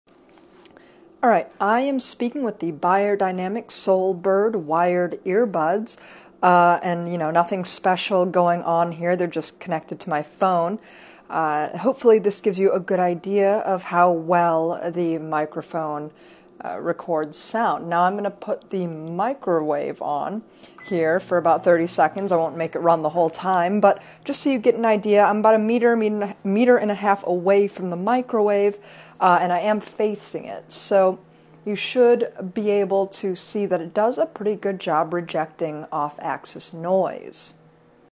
Beyerdynamic-Soul-Byrd-microphone-demo.mp3